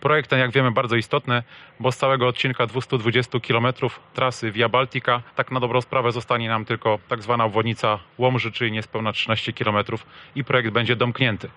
Tomasz Żuchowski, generalny dyrektor Dróg Krajowych i Autostrad dodał, że ten odcinek drogi pozwoli przybliżyć się do końca całej inwestycji: